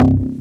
Hollow Bass.wav